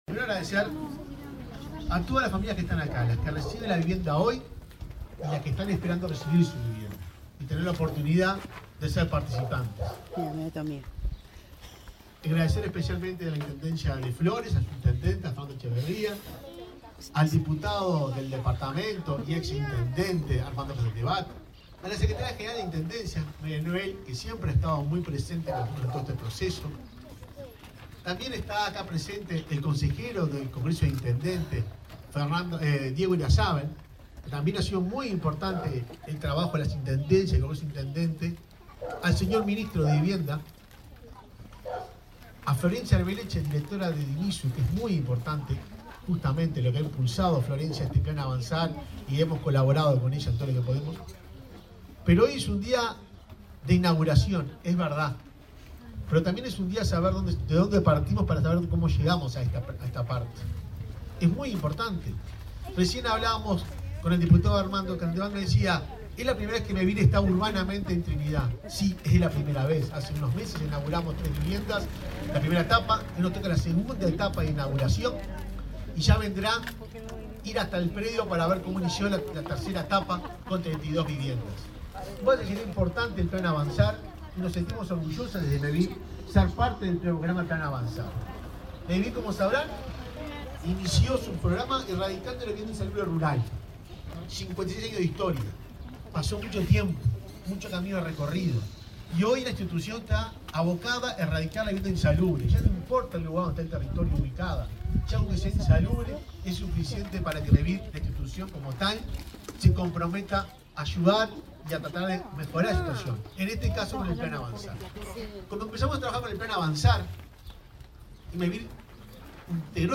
Palabra de autoridades en acto de Plan Avanzar en Flores
Palabra de autoridades en acto de Plan Avanzar en Flores 11/12/2023 Compartir Facebook X Copiar enlace WhatsApp LinkedIn El presidente de Mevir, Juan Pablo Delgado, la directora nacional de Integración Social y Urbana, Florencia Arbeleche, y el ministro de Vivienda, Raúl Lozano, participaron de lanzamiento del Plan Avanzar, en Trinidad, departamento de Flores.